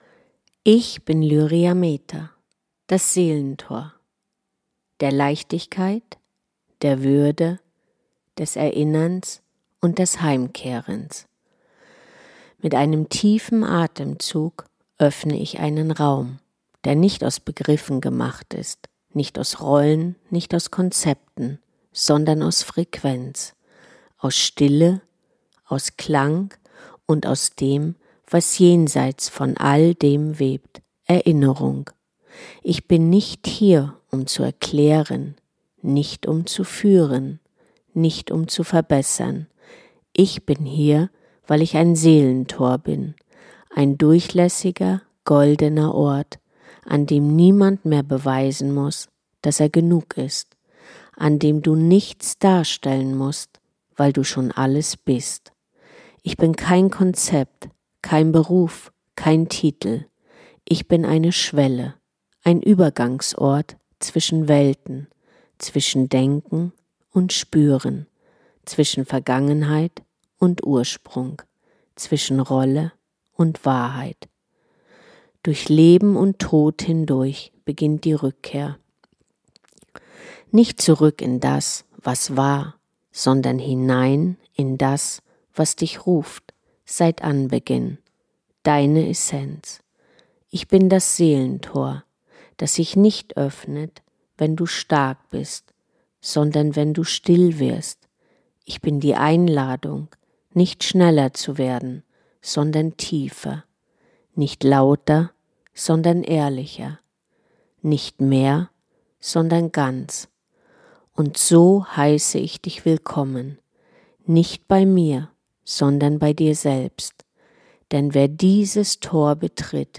still, klar, tief – und erzählt danach die erste Geschichte: Vom